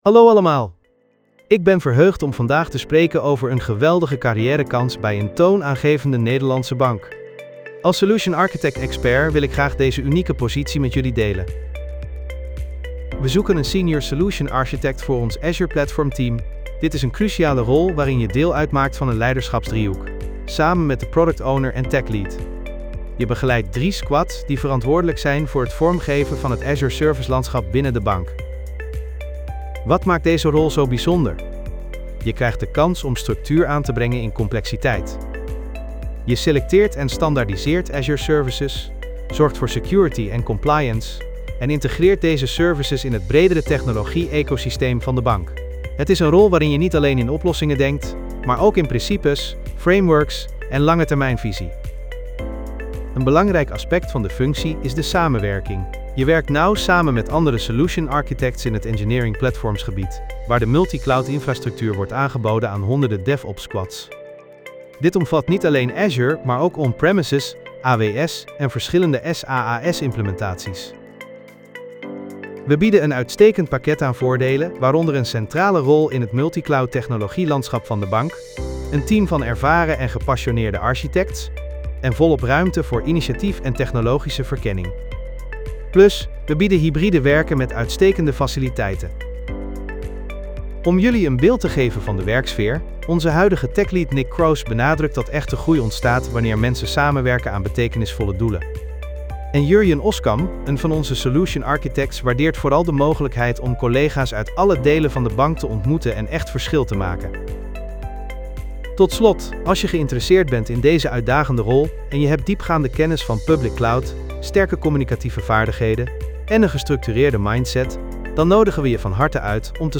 Podcast gegenereerd van tekst content (4569 karakters)